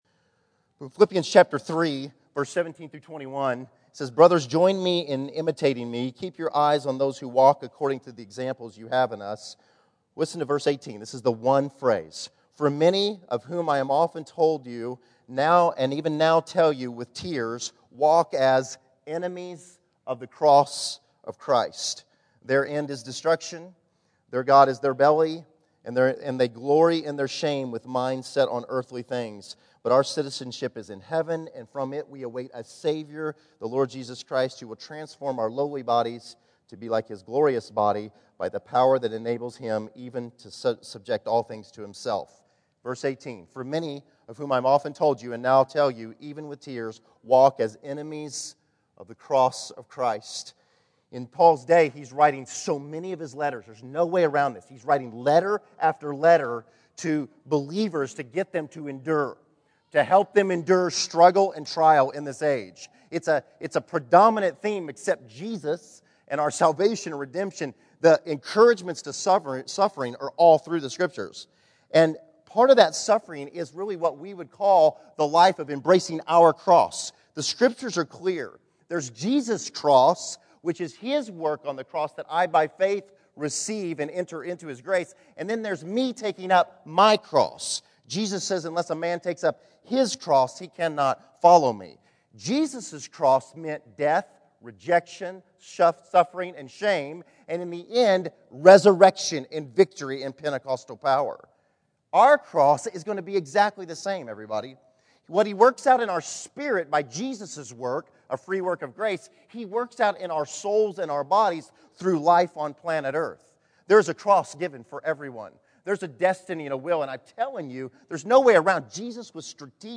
Category: Sermons | Location: El Dorado Back to the Resource Library